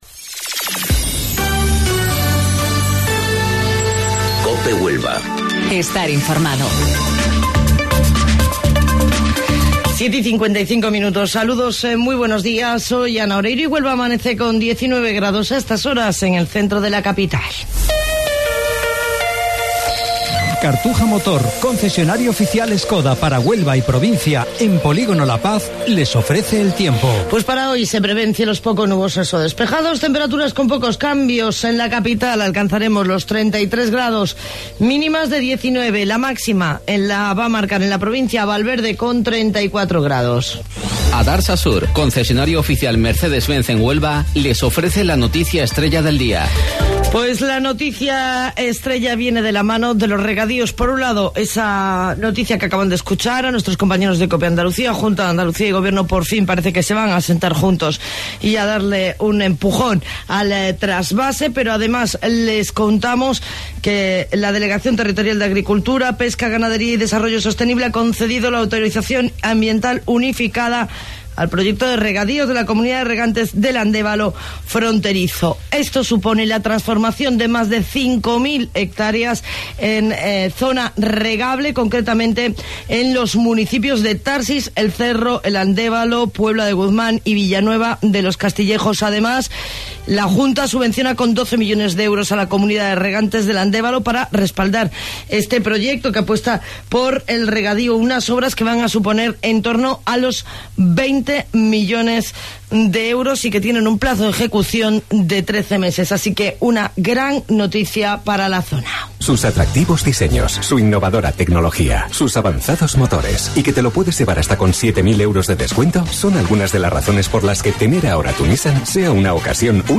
AUDIO: Informativo Local 07:55 del 1 de Agosto